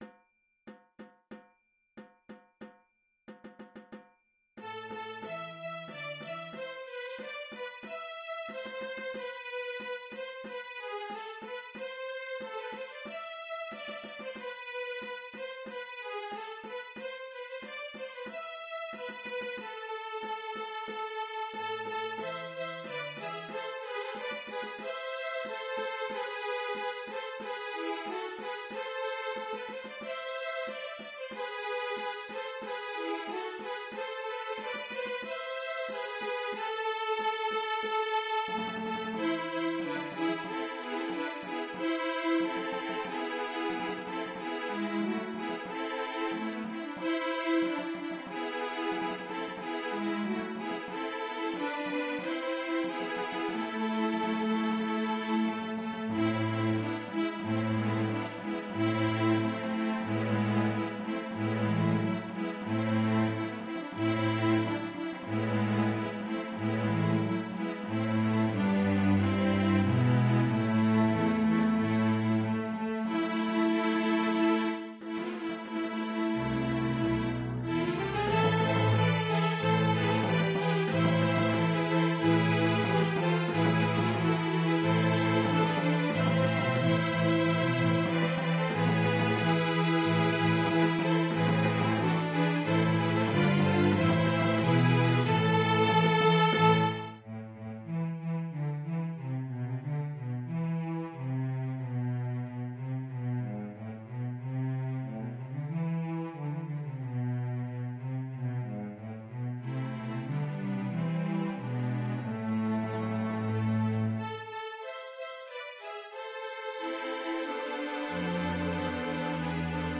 Composer: French Carol
Voicing: String Orchestra